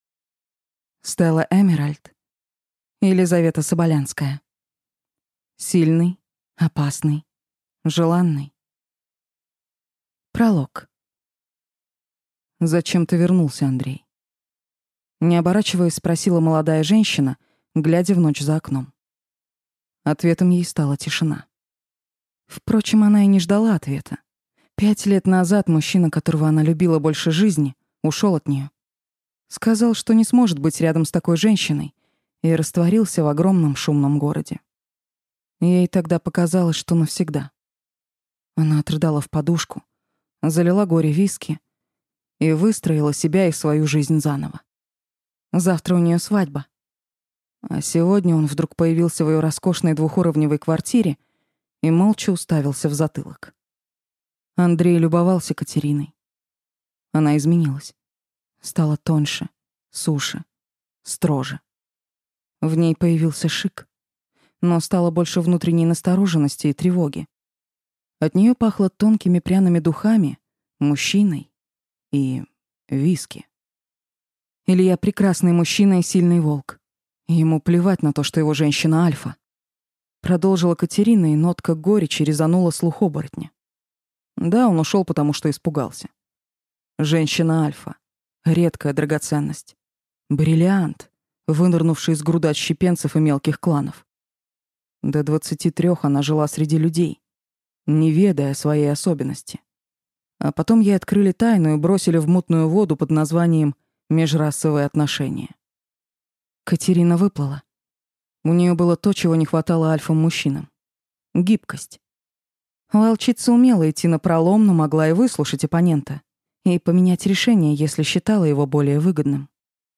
Аудиокнига Сильный, опасный, желанный | Библиотека аудиокниг